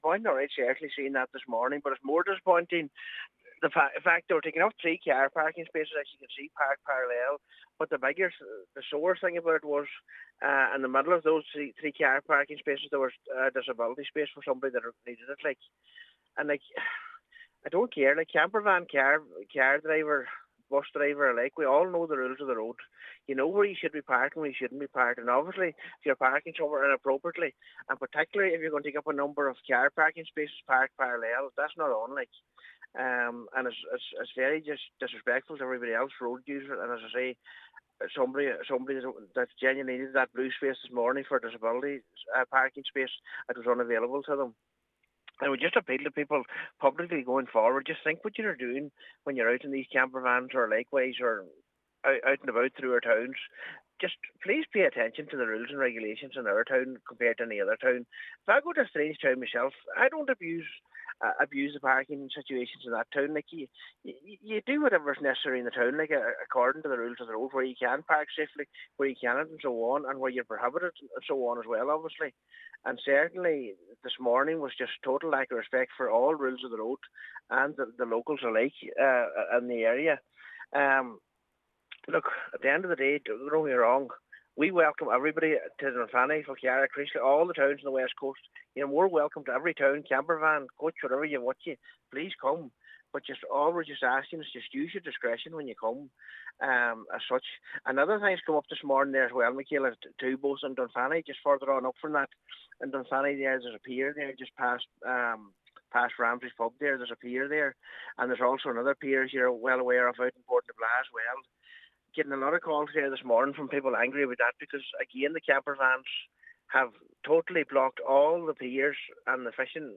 Councillor Michael McClafferty is appealing to campervan owners to show respect to the local areas they are visiting: